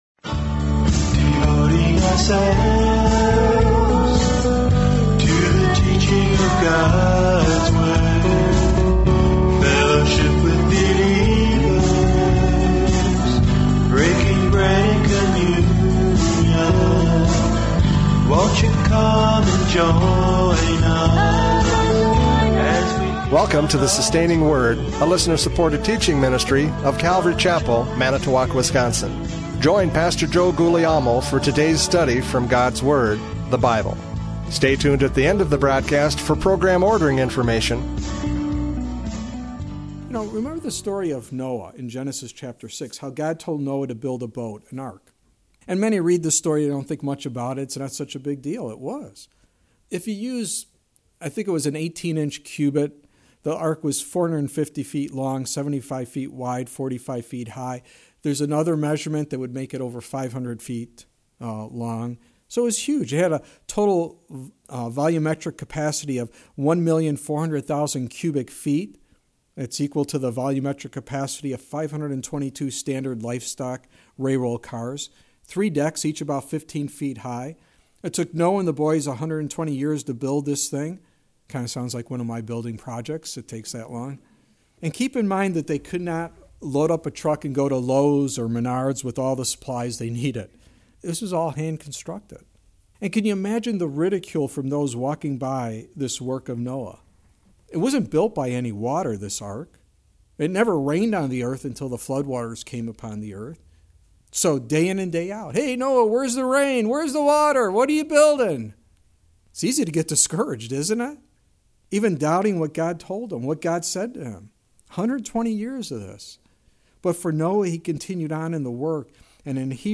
John 11:28-44 Service Type: Radio Programs « John 11:28-44 “But Lord